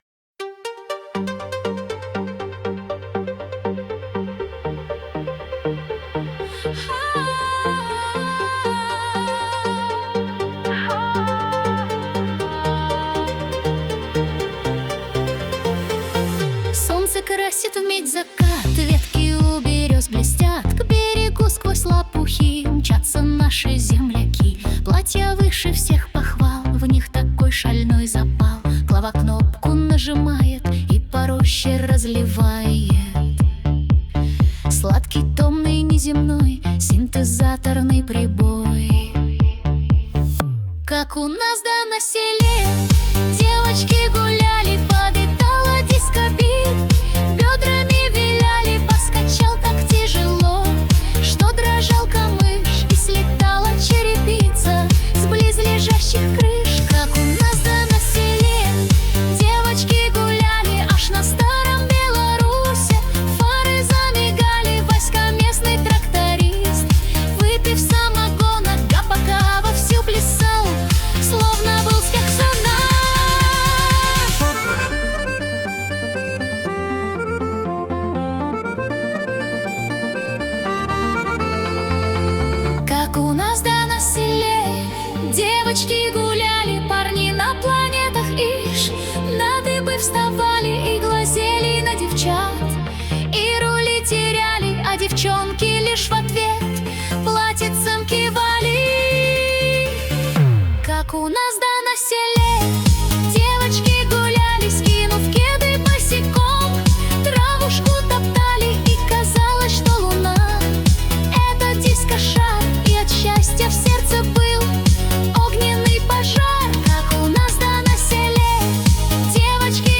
Techno-90s Style